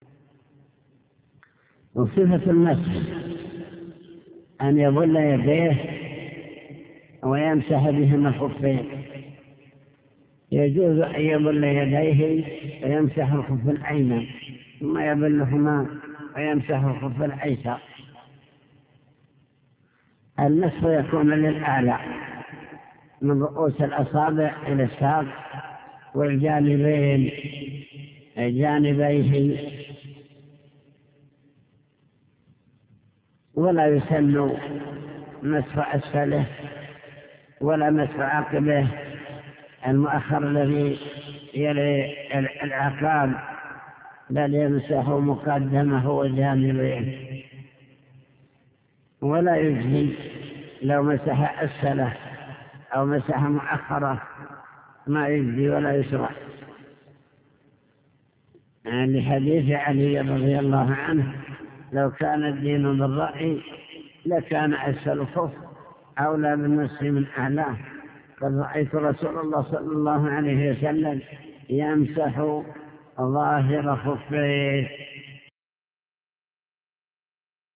المكتبة الصوتية  تسجيلات - كتب  شرح كتاب دليل الطالب لنيل المطالب كتاب الطهارة المسح على الخفين